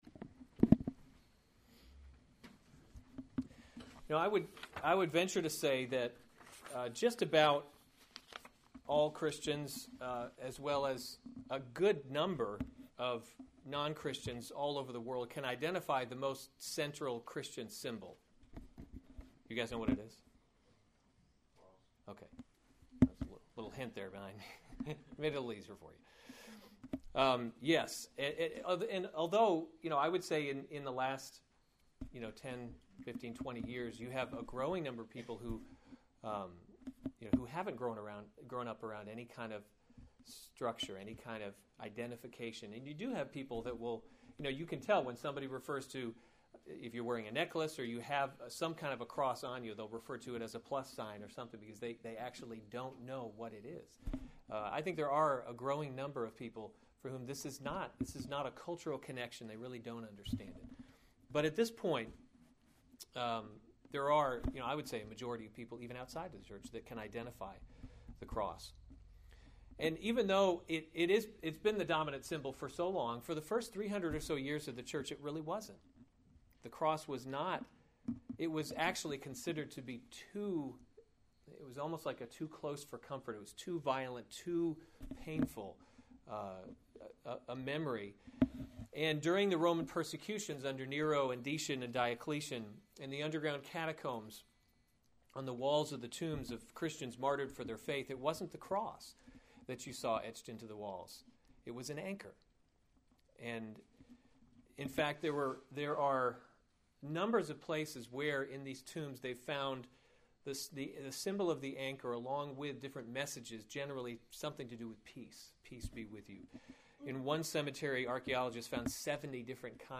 June 6, 2015 Romans – God’s Glory in Salvation series Weekly Sunday Service Save/Download this sermon Romans 16:25-27 Other sermons from Romans Doxology 25 Now to him who is able to […]